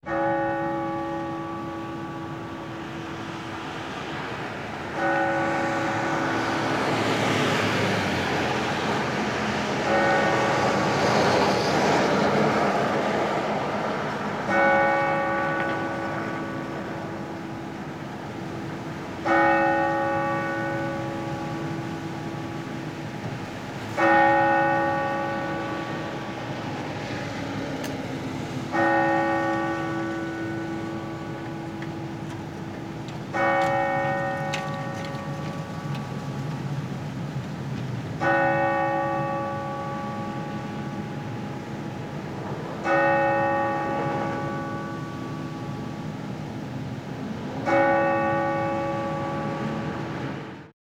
City Clock Bell